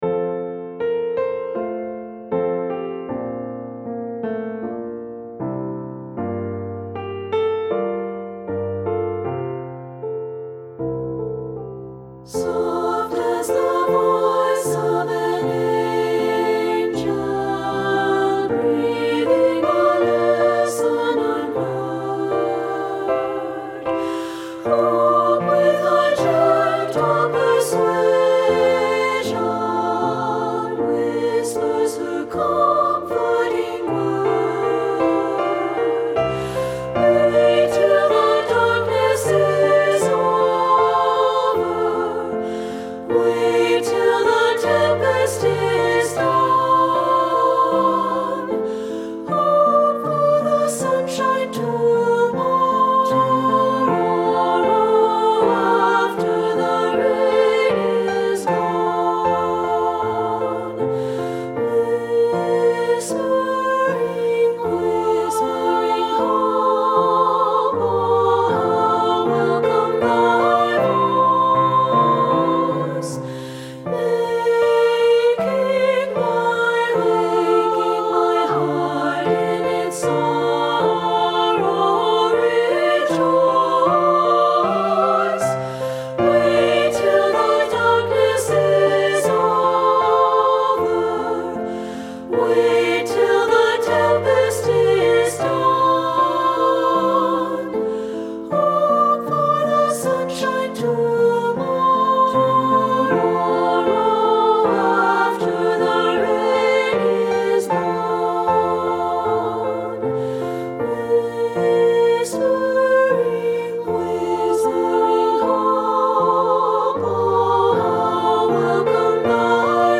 • Soprano
• Alto
• Piano
Studio Recording
Ensemble: Treble Chorus
Accompanied: Accompanied Chorus